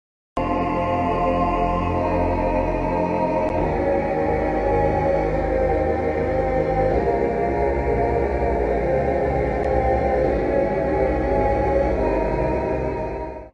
Slowed